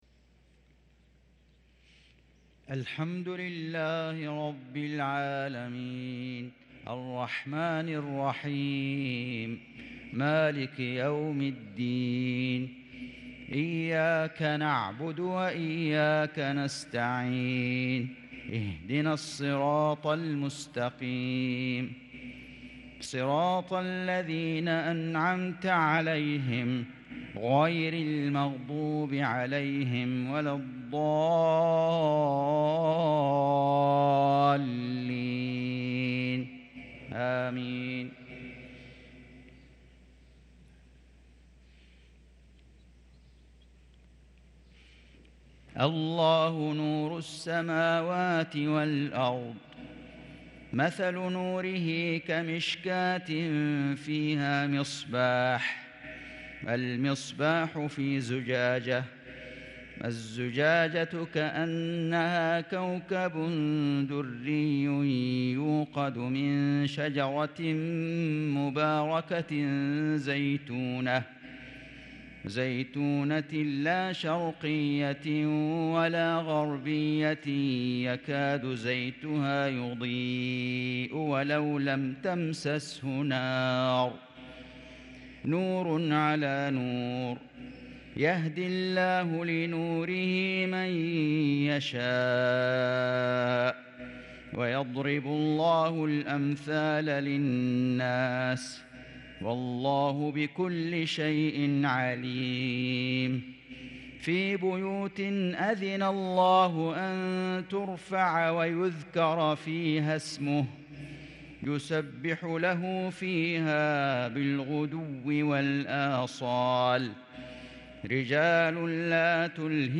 عشاء ٥-٨ -١٤٤٣هـ سورة النور | Isha prayer from surah an-Nur 8-3-2022 > 1443 🕋 > الفروض - تلاوات الحرمين